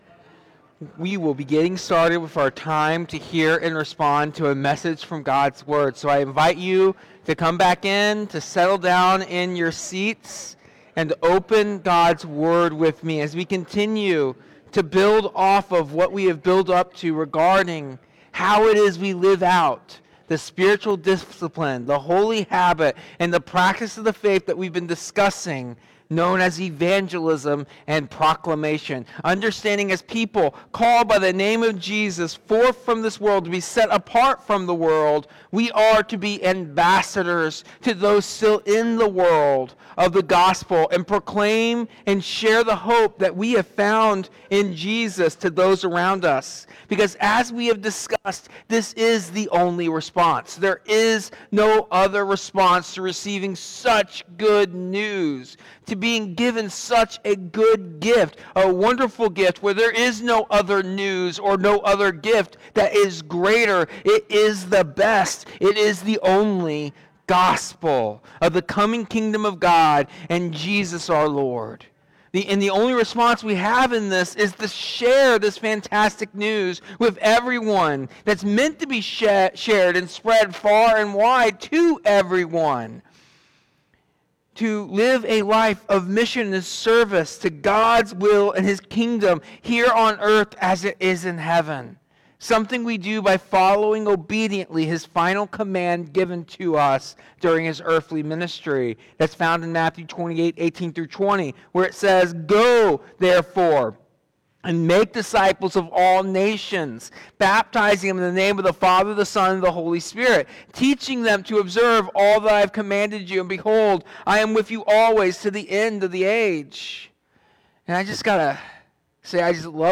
Today’s sermon teaches that evangelism flows from living like Jesus, who blessed others by praying, listening, eating with them, and serving them. Jesus modeled humble, sacrificial service—most vividly when He washed His disciples’ feet the night before His death.